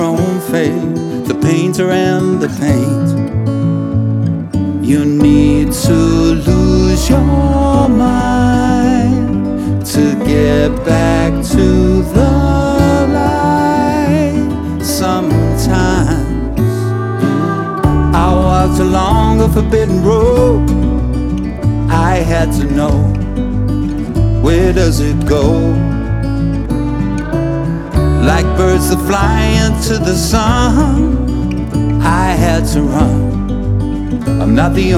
Жанр: Поп / Музыка из фильмов / Саундтреки